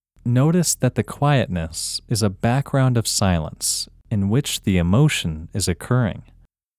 OUT – English Male 10